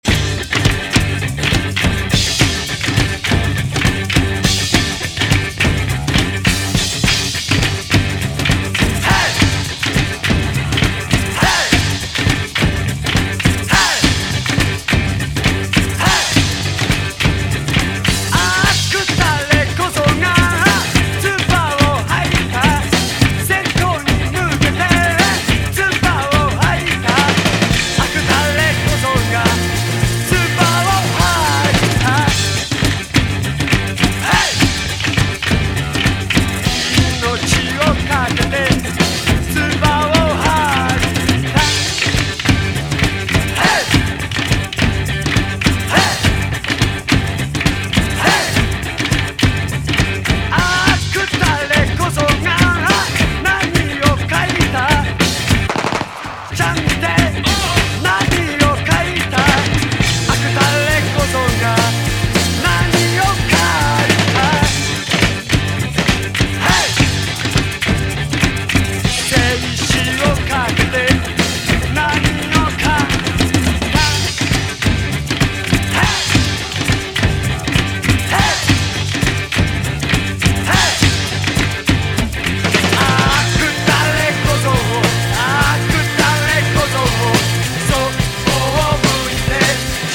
モッドでヒップな和モノ・グルーヴ！ゴー・ゴー・アレンジでヒット・ポップス・カヴァー！